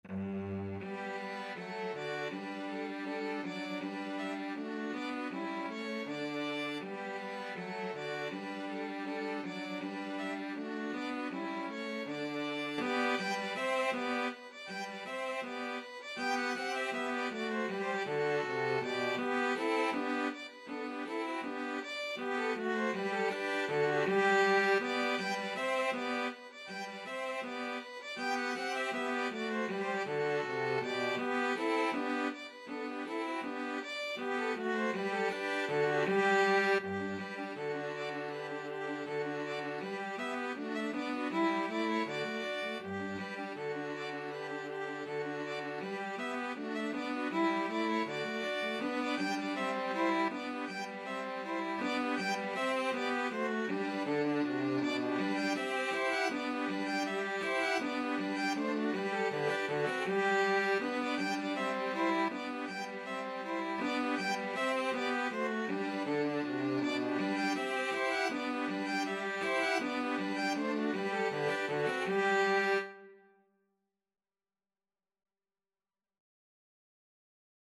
Free Sheet music for 2-Violins-Cello
Violin 1Violin 2Cello
G major (Sounding Pitch) (View more G major Music for 2-Violins-Cello )
Andante cantabile
2/4 (View more 2/4 Music)
Classical (View more Classical 2-Violins-Cello Music)